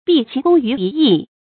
畢其功于一役 注音： ㄅㄧˋ ㄑㄧˊ ㄍㄨㄙ ㄧㄩˊ ㄧ ㄧˋ 讀音讀法： 意思解釋： 把應該分成幾步做的事一次做完。